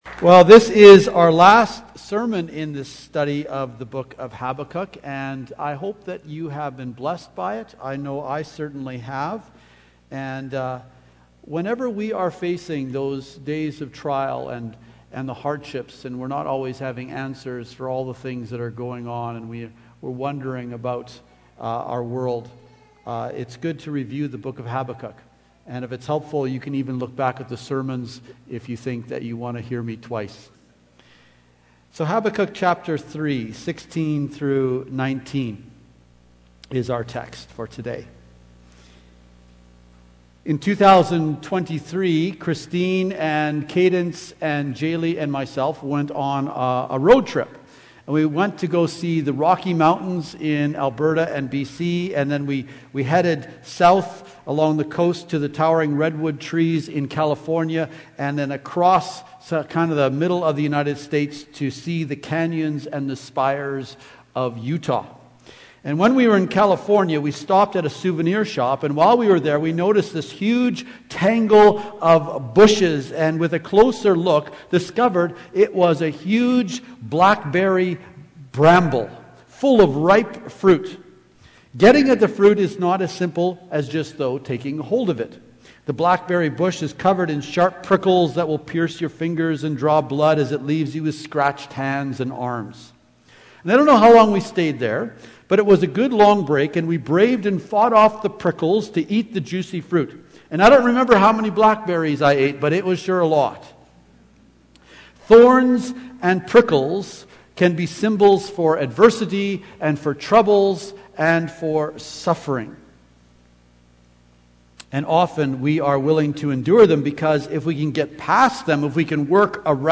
This sermon teaches us how we can rejoice in the God of our salvation even in the midst of fear and loss.